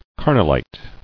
[car·nall·ite]